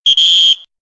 AA_sound_whistle.ogg